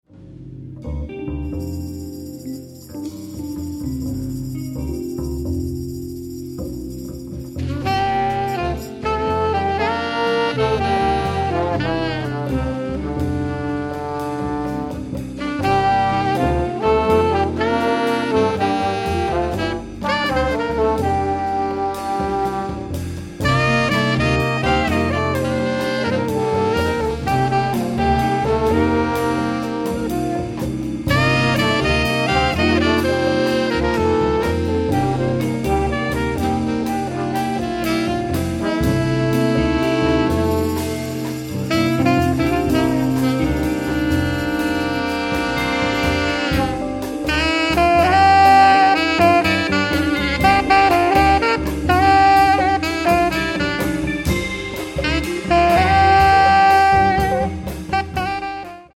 sax, bass, vocals
percussion
keyboard
trombone
saxes